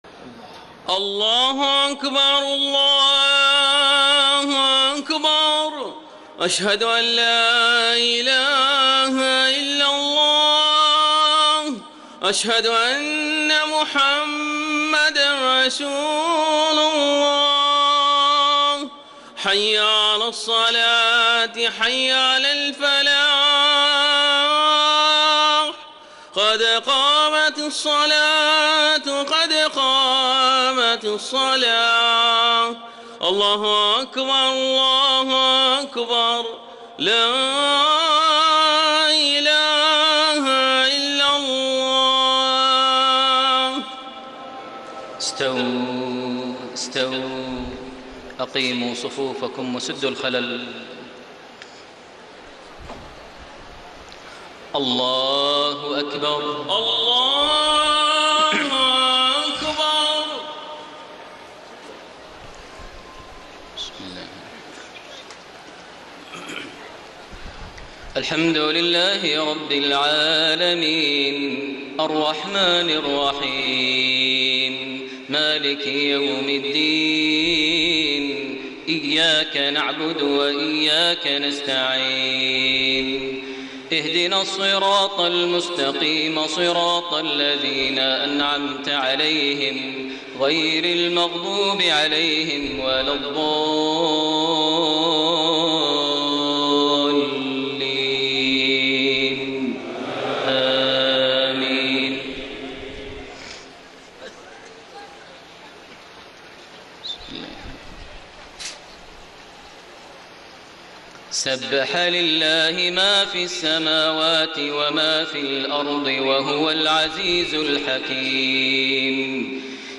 صلاة العشاء 1 شعبان 1433هـ سورة الصف 1-13 > 1433 هـ > الفروض - تلاوات ماهر المعيقلي